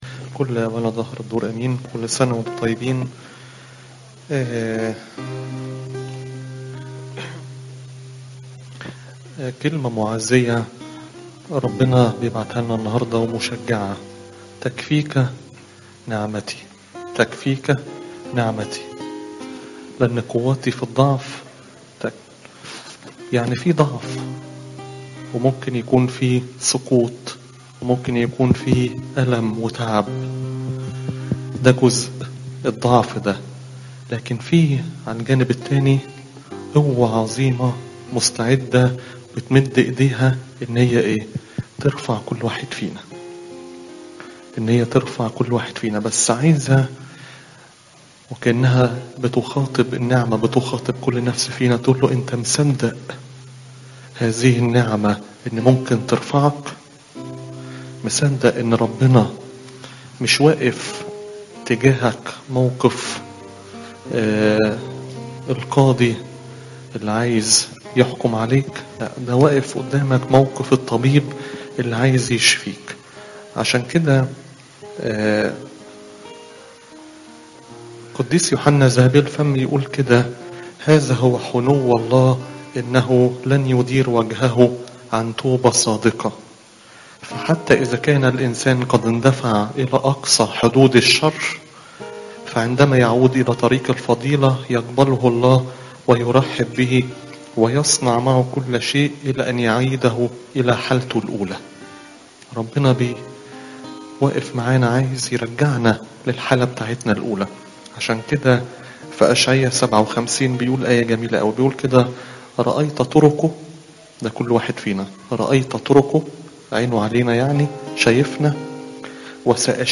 تفاصيل العظة